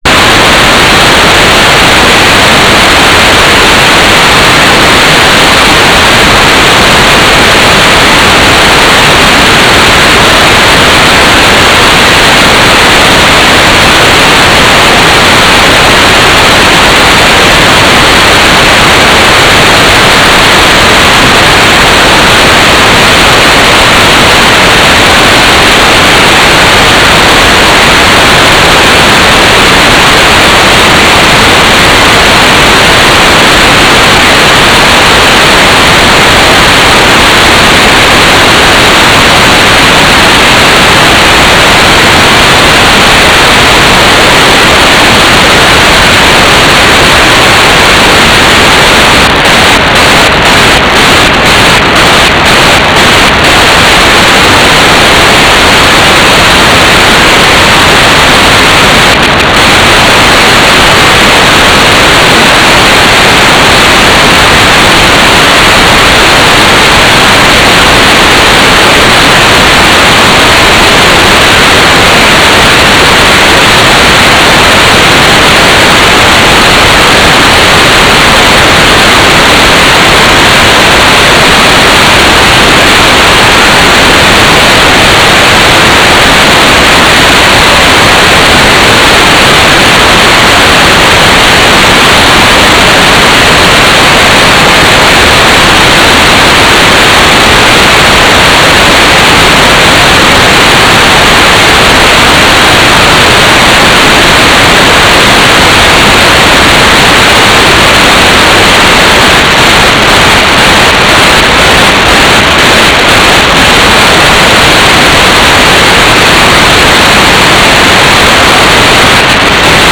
"transmitter_description": "Mode U - GFSK4k8 - AX.25 - Telemetry",
"transmitter_baud": 4800.0,